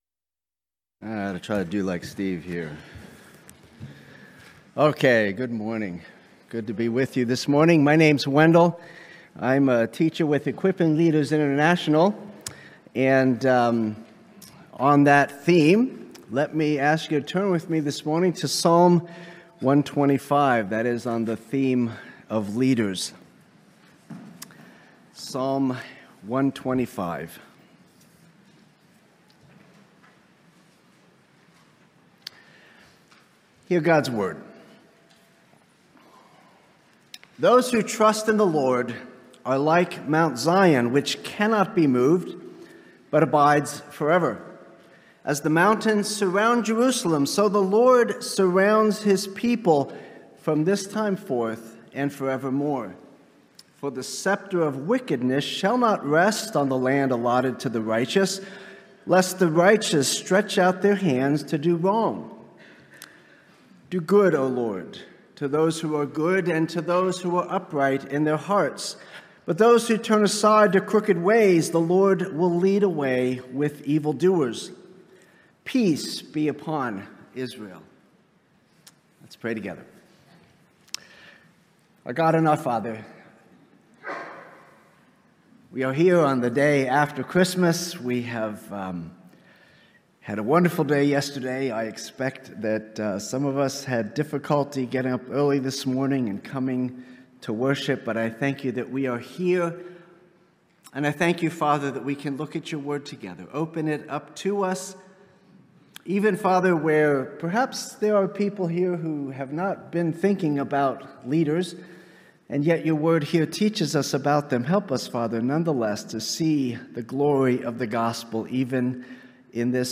Sermon Outline Those who trust in God are secure because God is immovable.